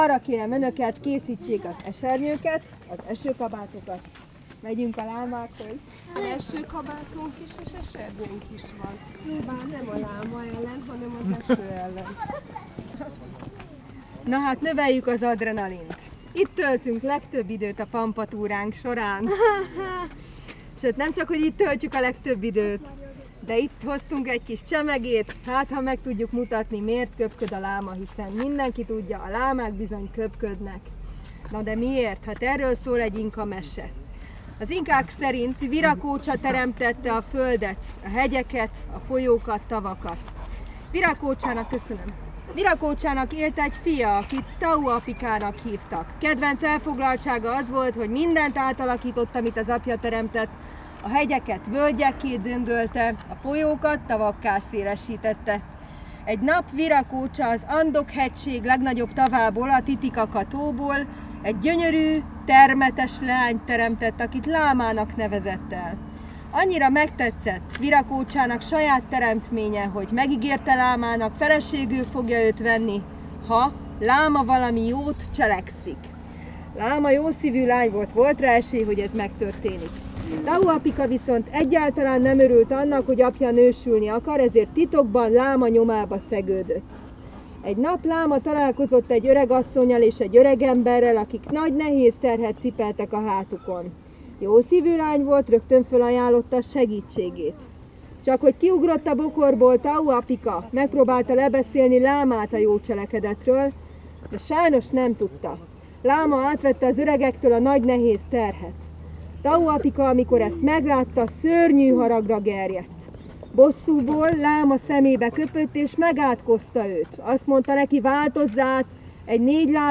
latvanyetetes_zoopedagogus04.05.wav